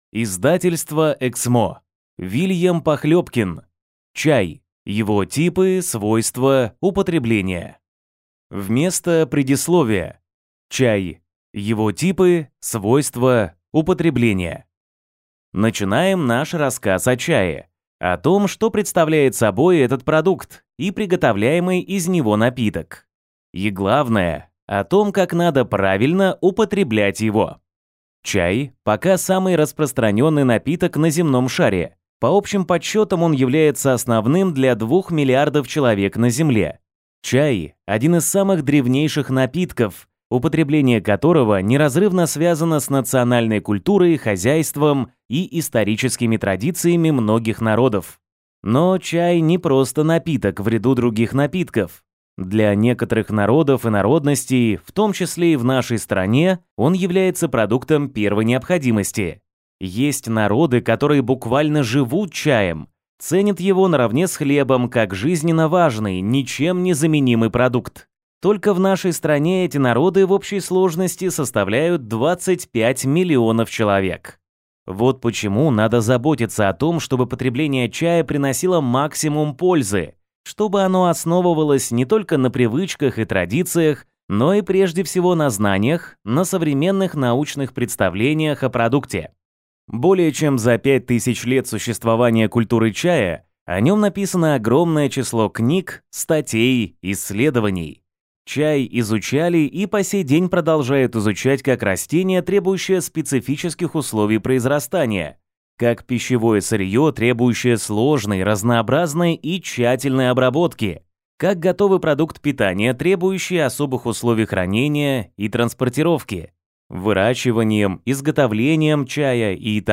Аудиокнига «Свободу демонам! Том 4».